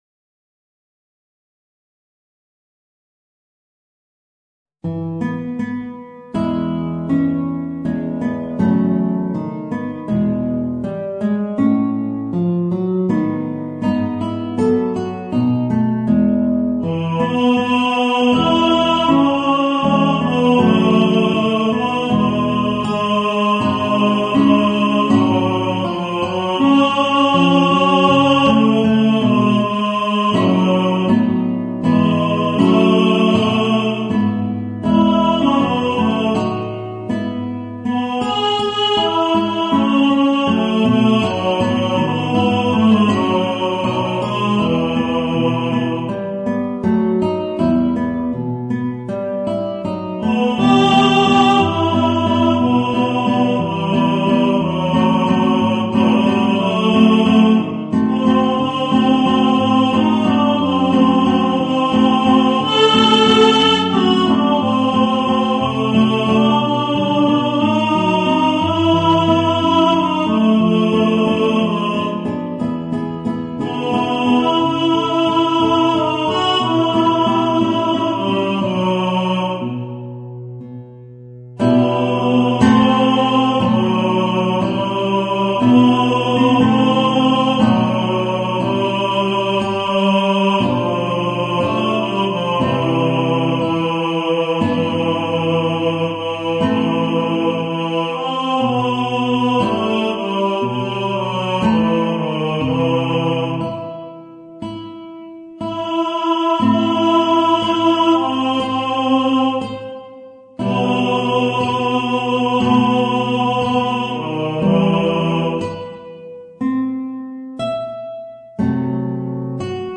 Voicing: Guitar and Tenor